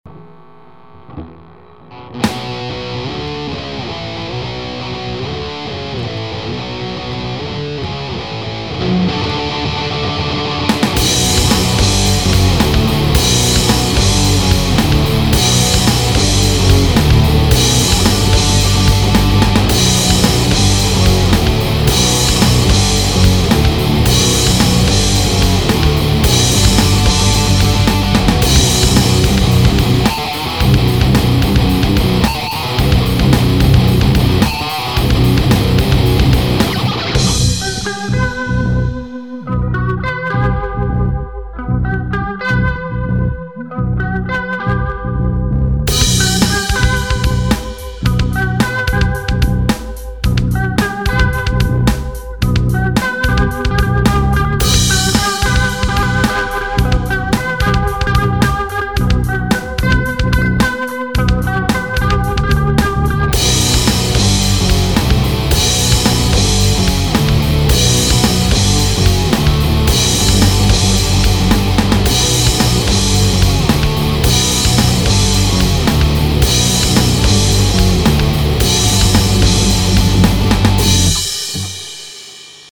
он басист-)